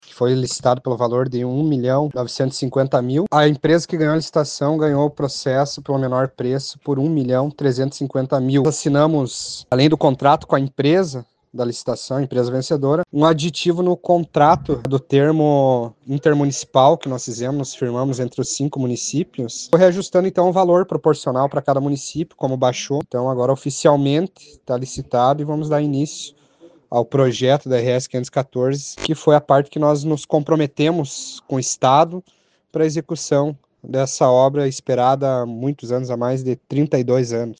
O prefeito de Condor, Rômulo Teixeira, ressalta que pela modalidade de licitação por menor preço, foi possível economia.